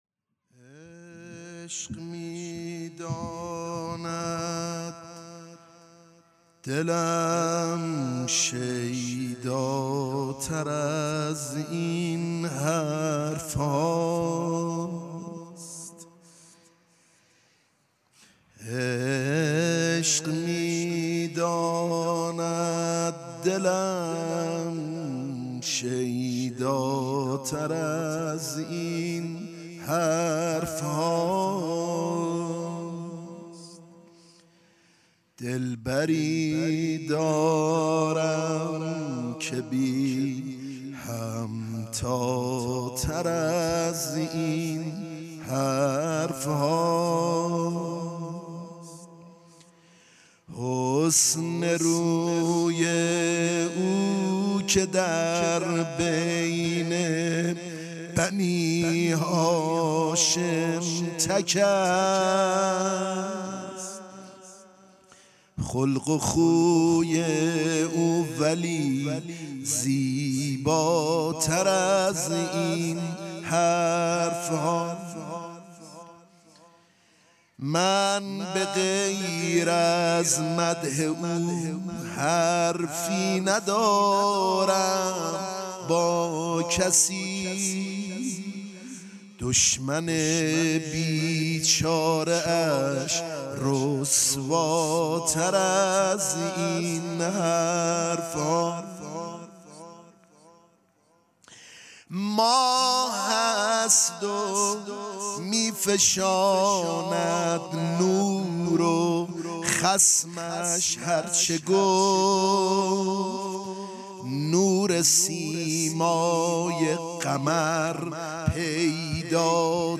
روضه خوانی
در شب نهم محرم ۱۴۰۱ در مقبره شهدای گمنام شهرک شهید محلاتی